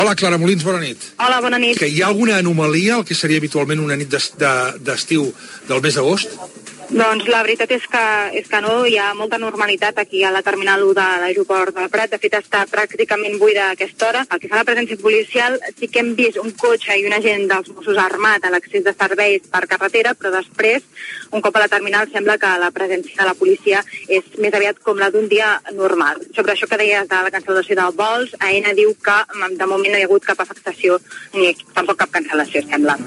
Informació, des de l'aeroport del Prat (Barcelona), a la nit del dia dels atemptats fets a la Rambla de Barcelona i Cambrils i reivindicats per Estat Islàmic
Informatiu
FM